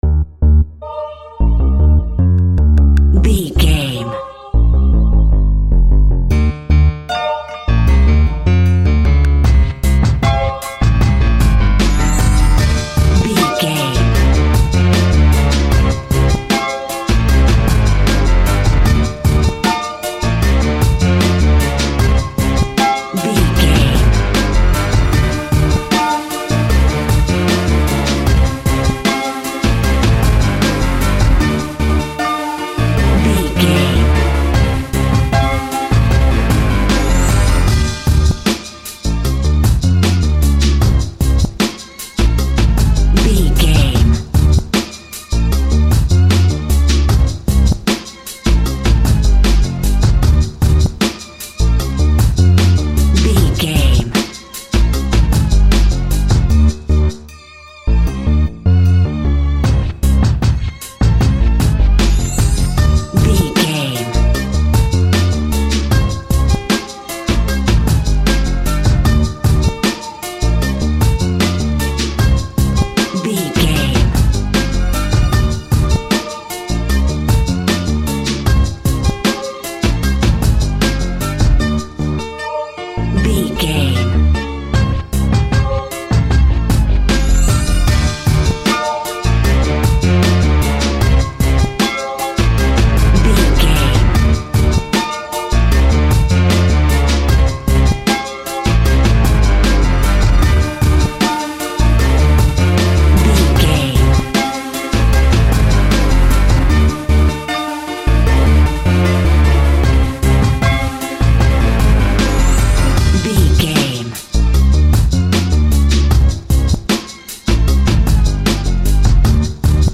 Dirty Funky Rap Cue.
Aeolian/Minor
D
instrumentals
chilled
laid back
groove
hip hop drums
hip hop synths
piano
hip hop pads